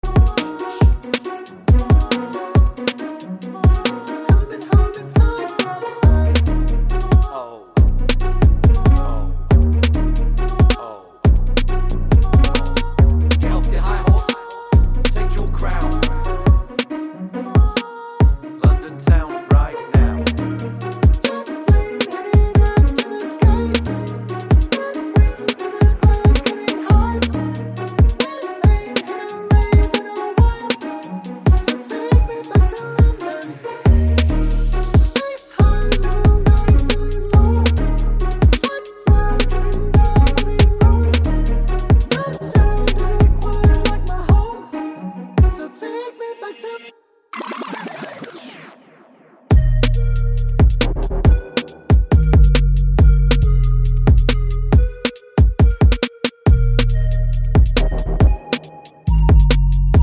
Remix with No Backing Vocals R'n'B / Hip Hop 3:50 Buy £1.50